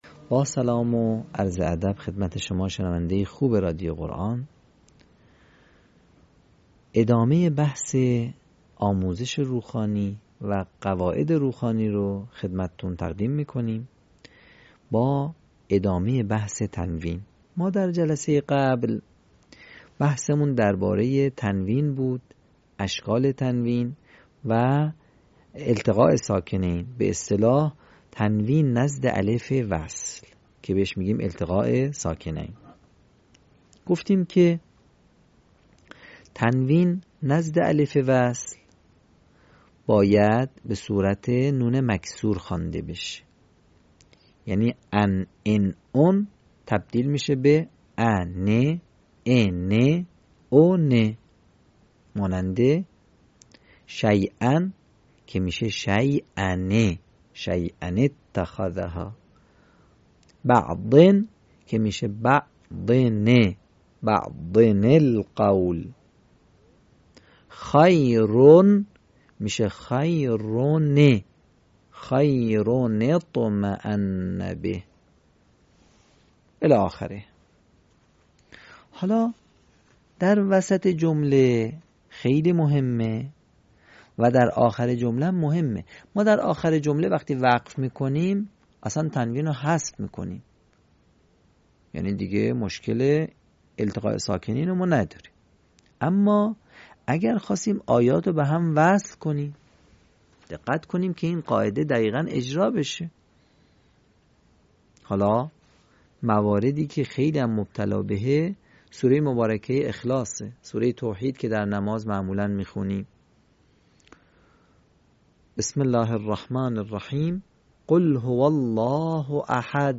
صوت | آموزش روخوانی «تنوین»